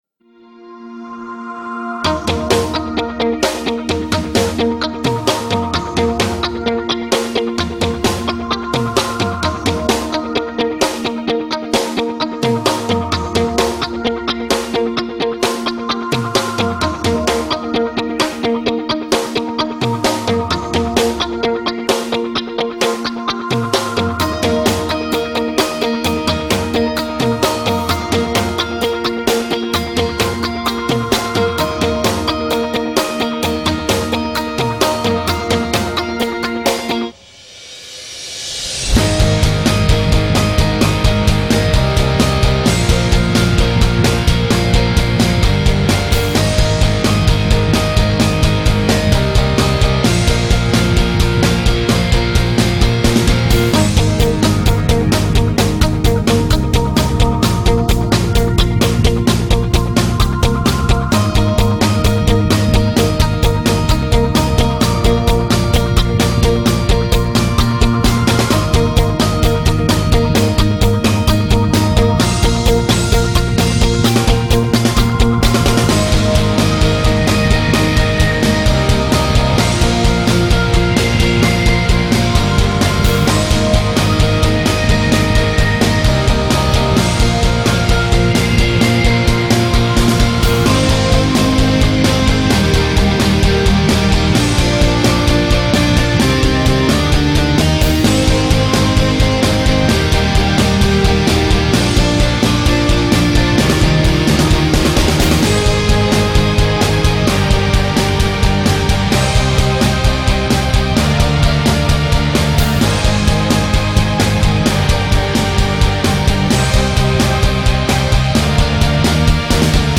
Remix и Instrumental Дорогие пользователи!